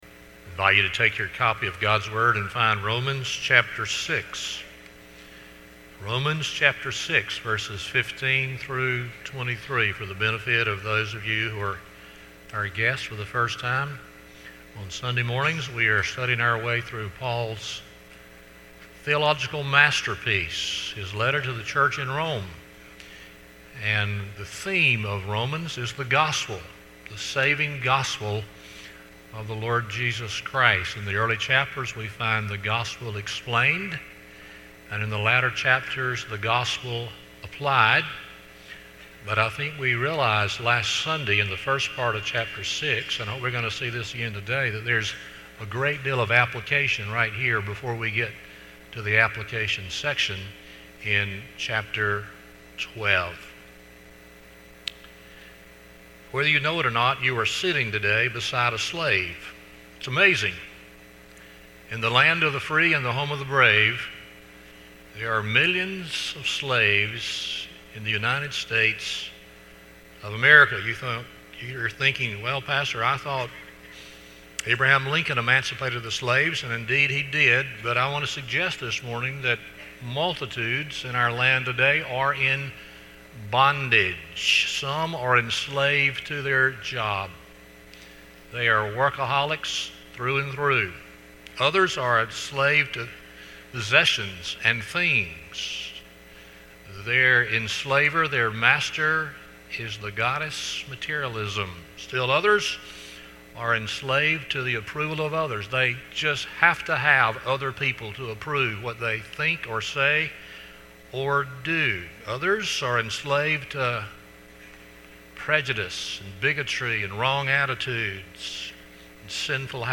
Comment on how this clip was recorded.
Romans 6:15-22 Service Type: Sunday Morning 1.